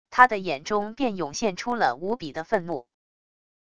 他的眼中便涌现出了无比的愤怒wav音频生成系统WAV Audio Player